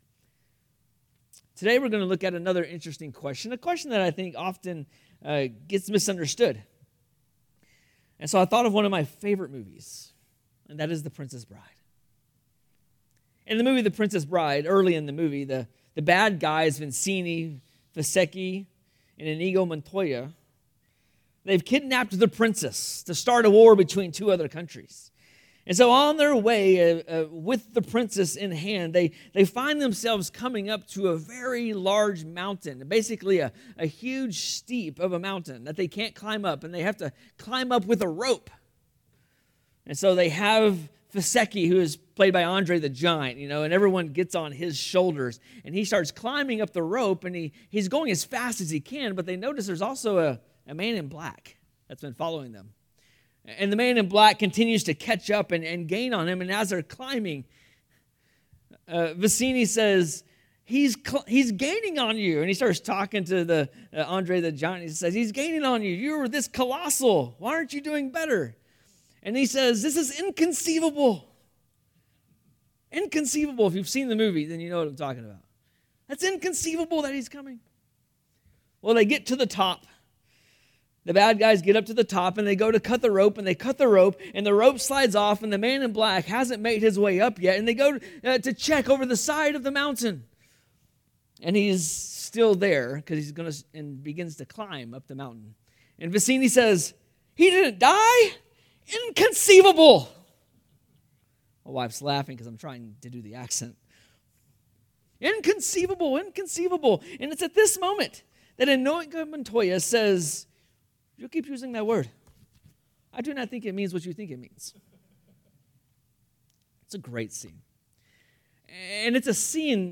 Sermons | Gainesville Bible Church